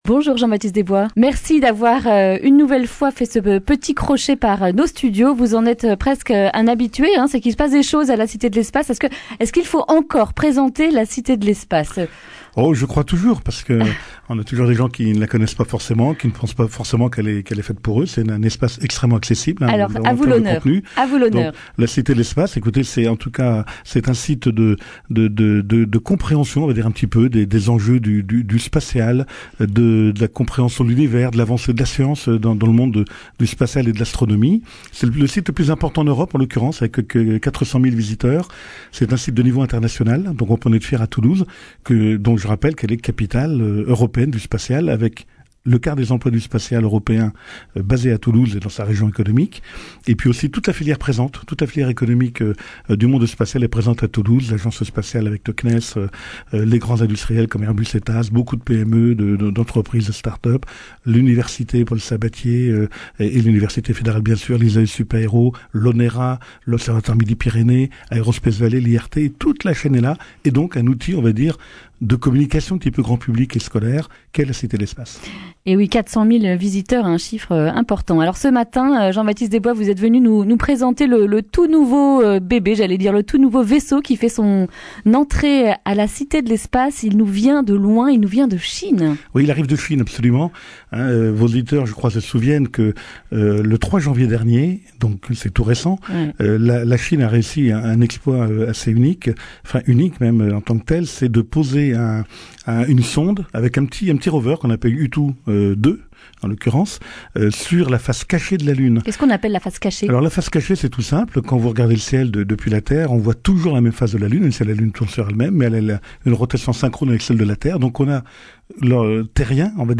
jeudi 12 septembre 2019 Le grand entretien Durée 10 min
Une émission présentée par